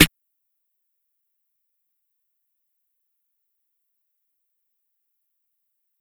pbs - reggae [ Snare ].wav